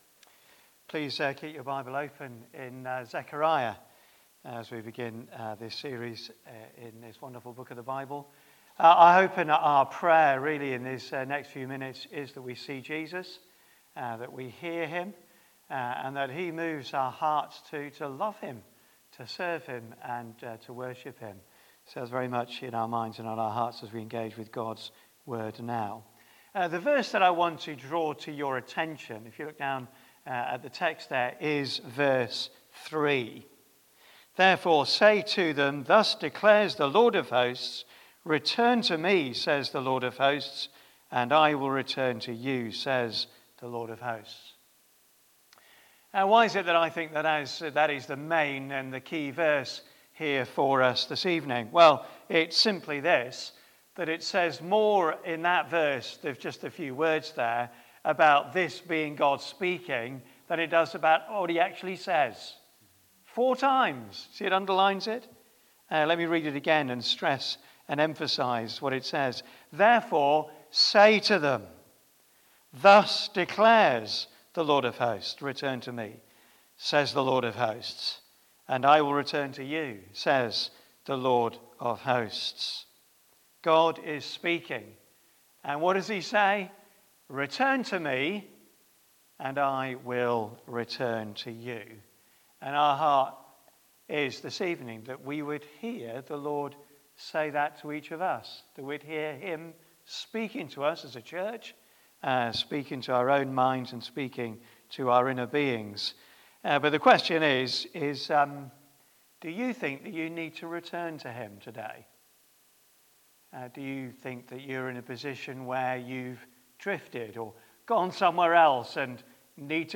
Bible Talks • Christ Church Central • Sheffield